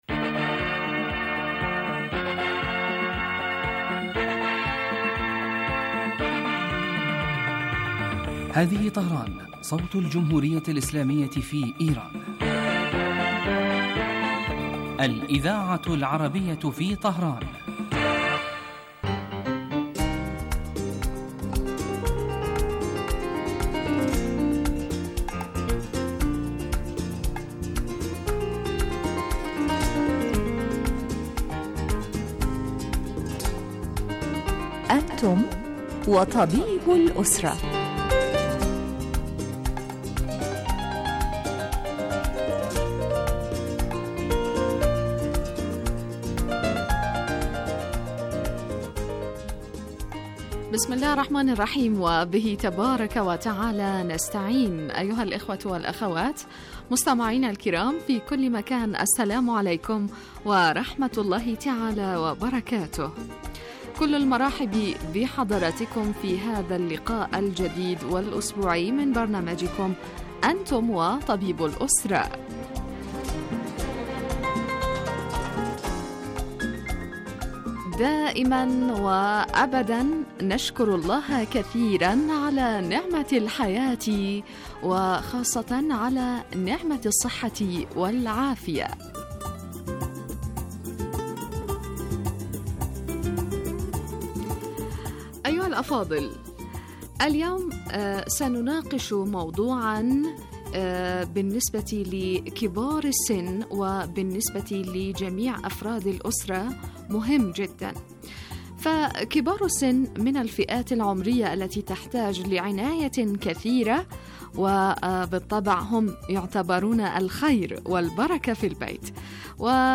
يتناول البرنامج بالدراسة والتحليل ما يتعلق بالأمراض وهو خاص بالأسرة ويقدم مباشرة من قبل الطبيب المختص الذي يرد كذلك علي أسئلة المستمعين واستفساراتهم الطبية ويبث صباح أيام الأربعاء وعلى مدى خمسة وأربعين دقيقة